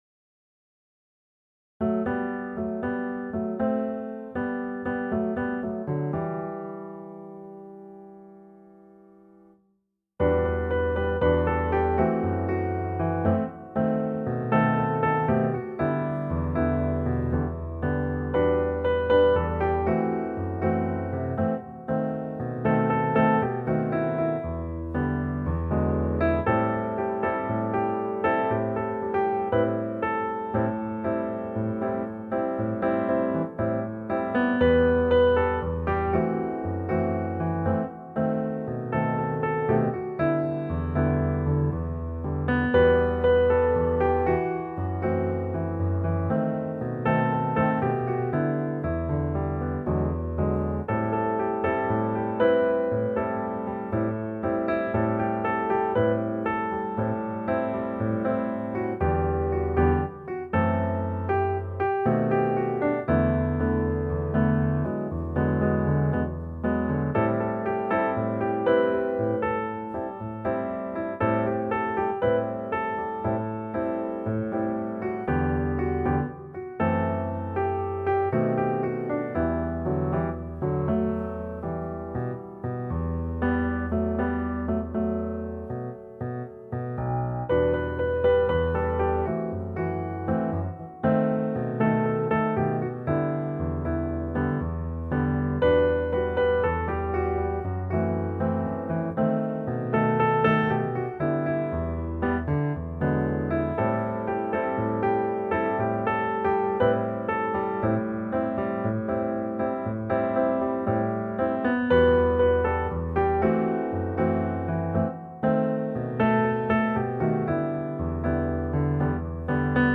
akompaniament